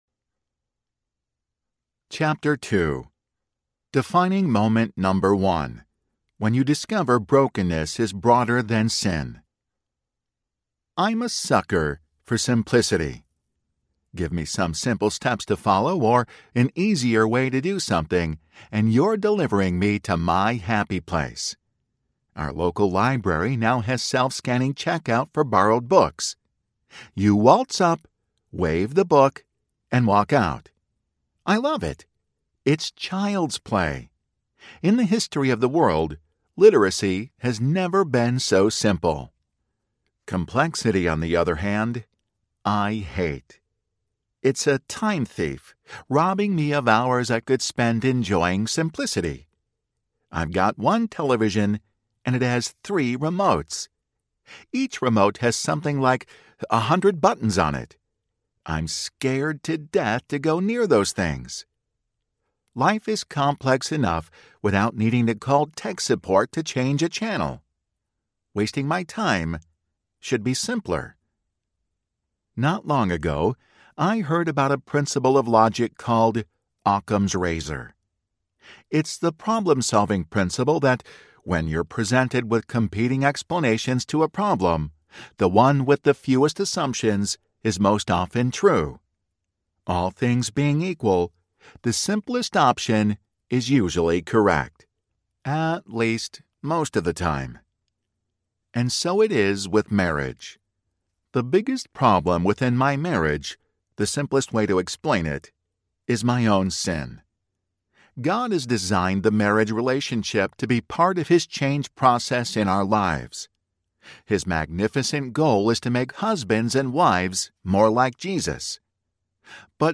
I Still Do Audiobook
Narrator
7.7 Hrs. – Unabridged